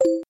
Vypočuj si upozornenie aplikácie Google Talk:
Vyzváňanie aplikácie Google Talk
GoogleTalkDing.mp3